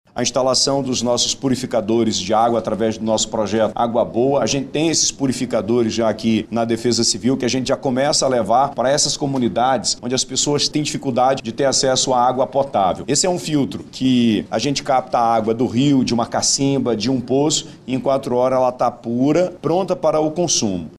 O governo do estado iniciou a operação estiagem junto com as secretarias de Educação, Saúde e Produção Rural e também com a Defesa Civil, para dar apoio as pessoas que moram nessas cidades. Nos municípios da calha do Rio Solimões por exemplo, muitas famílias estão com dificuldade para ter acesso a água potável, e por isso foi estabelecido algumas medidas para minimiza o problema, como explica o governador do Amazonas, Wilson Lima.